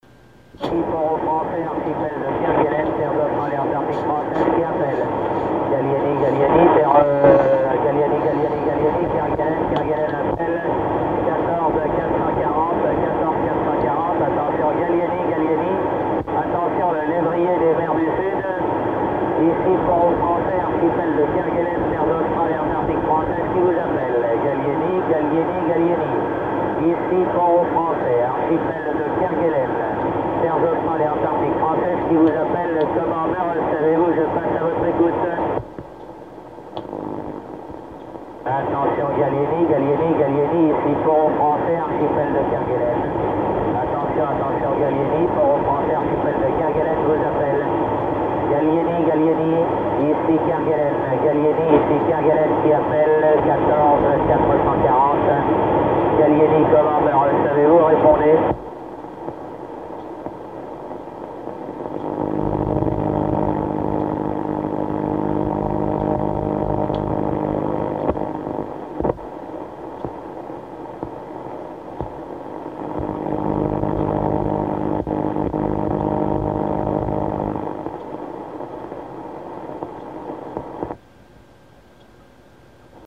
Enregistrement effectué sur le récepteur de trafic du GRI.